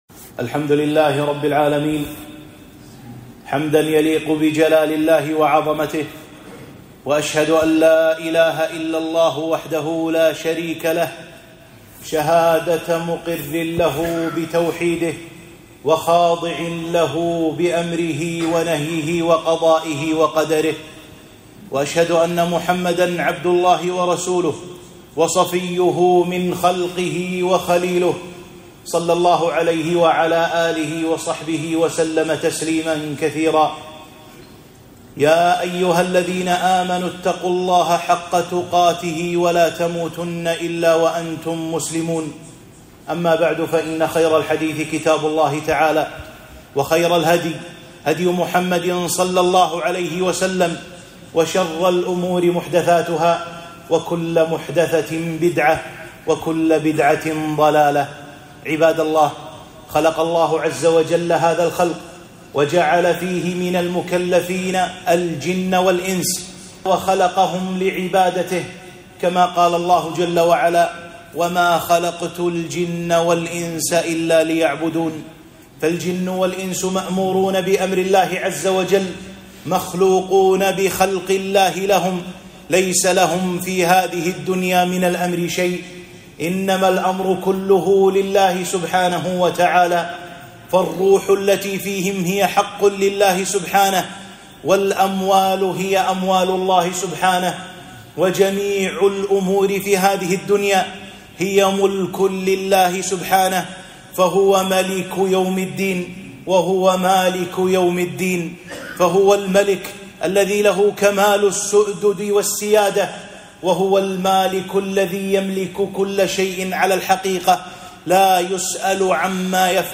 خطبة - خطر المال العام، والتحذير من كبيرة الربا